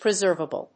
音節pre・serv・a・ble 発音記号・読み方
/prɪzˈɚːvəbl(米国英語)/